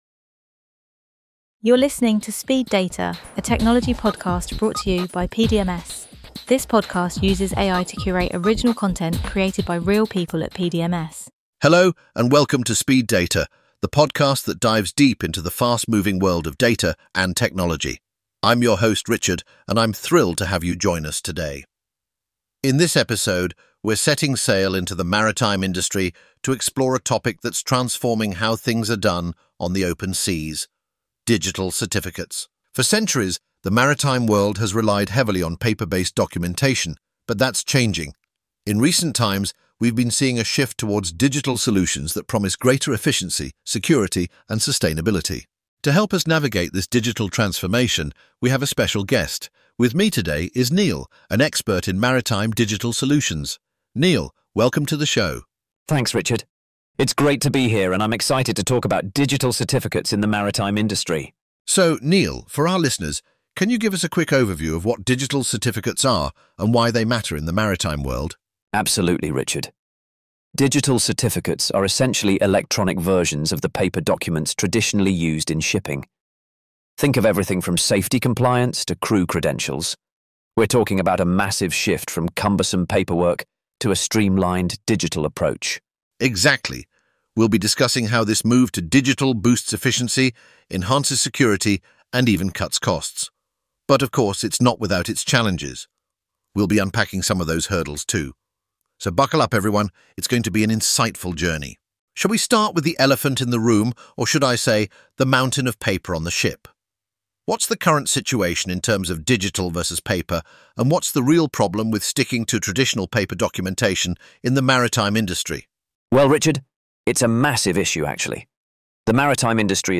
Every episode is generated using AI to help us deliver valuable content faster and more efficiently but grounded in the trusted and real-world experience of our team.